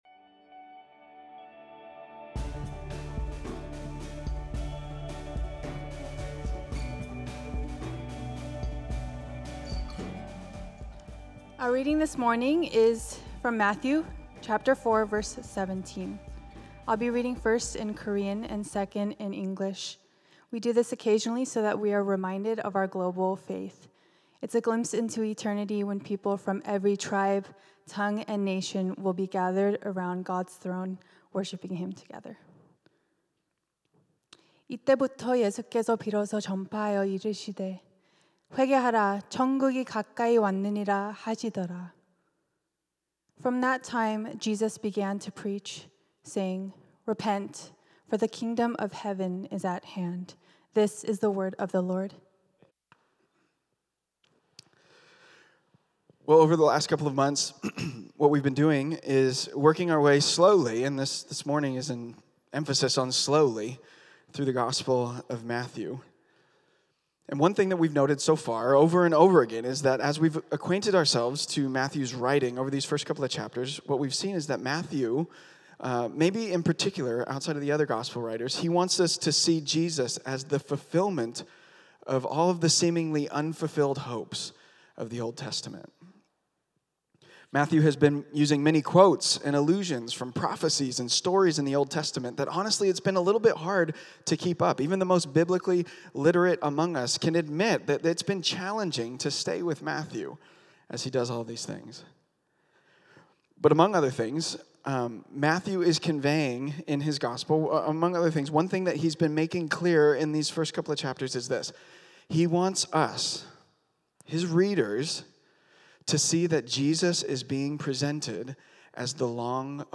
Bible teachings from Reality Church Stockton.